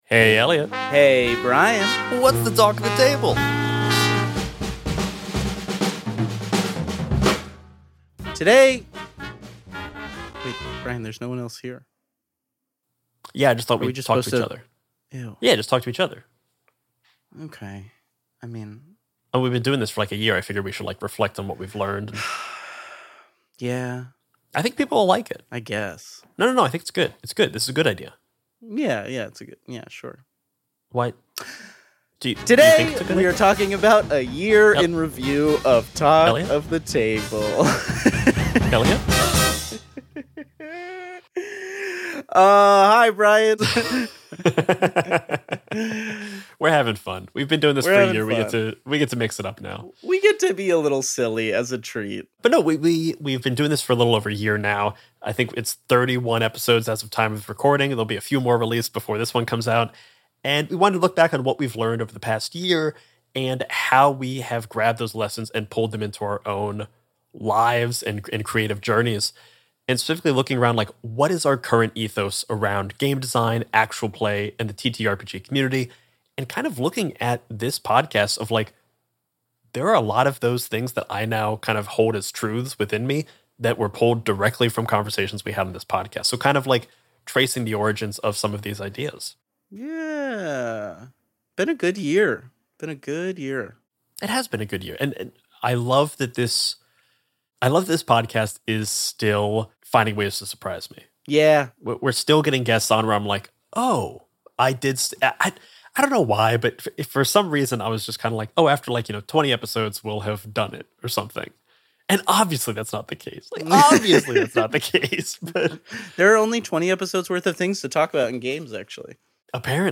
Talk of the Table is a TTRPG talk show